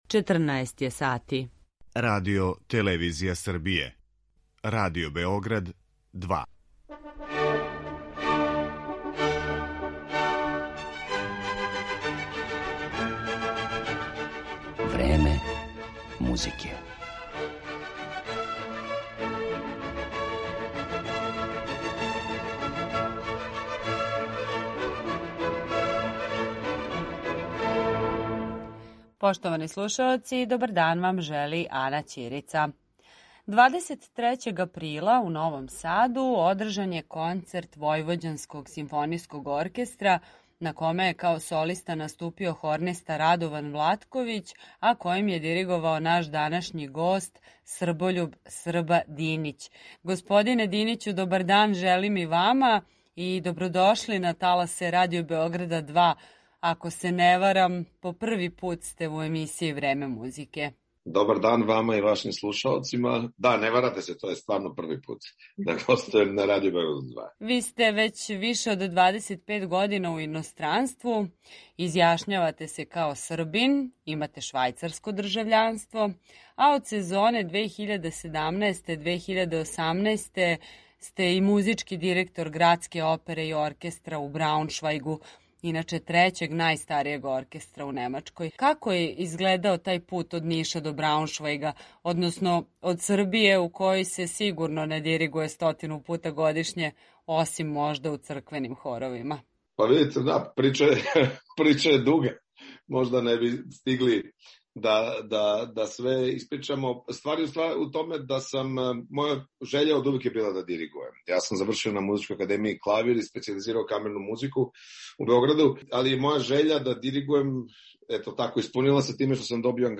Гост Времена музике.